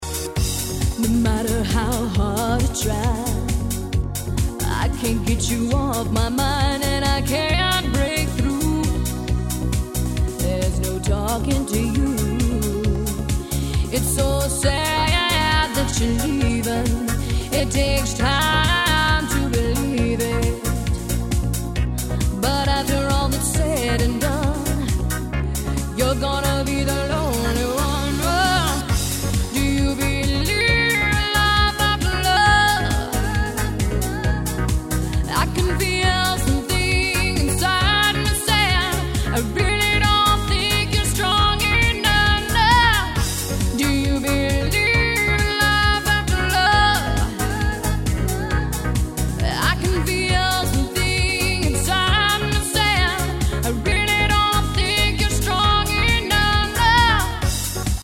P  O  P     and    R  O  C  K      C  O  V  E  R  S